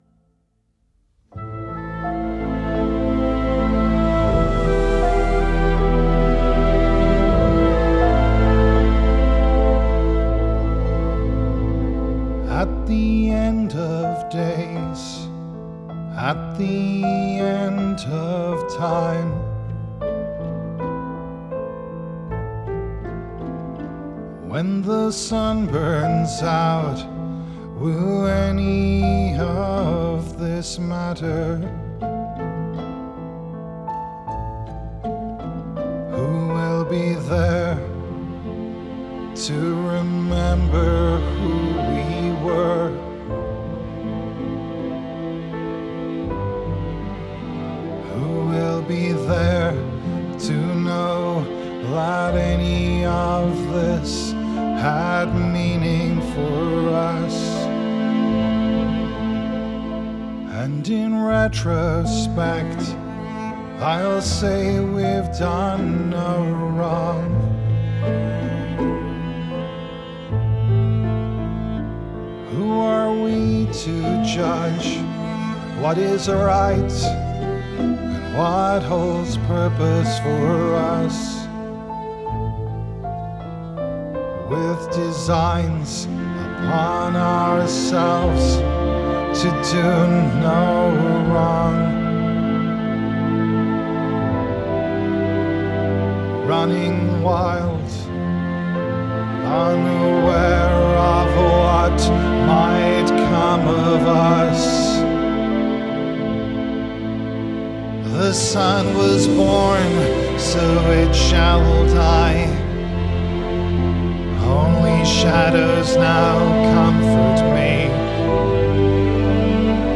arranged purely for voice and orchestra
Genre: Classical, Electronic